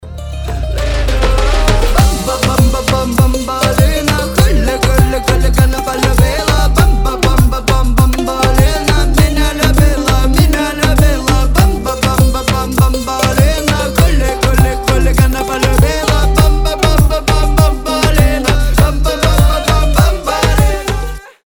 • Качество: 320, Stereo
гитара
ритмичные
зажигательные
веселые
dancehall